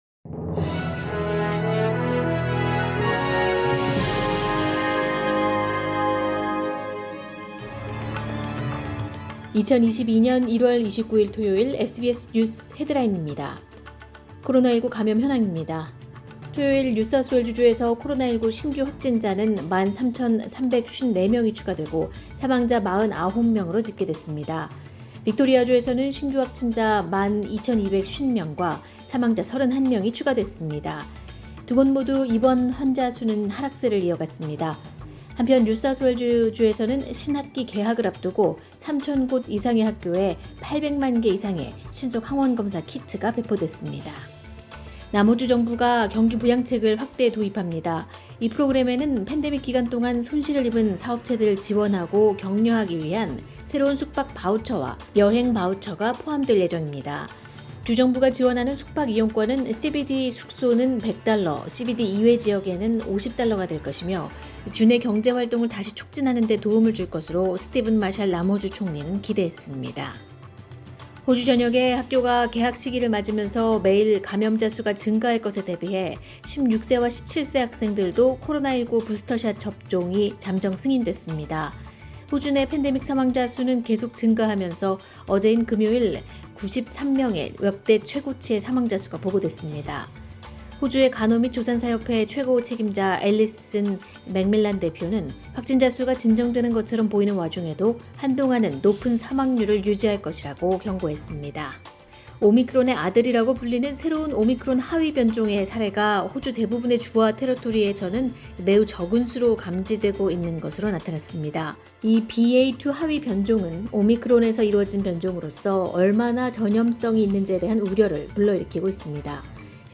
2022년 1월 29일 토요일 SBS 뉴스 헤드라인입니다.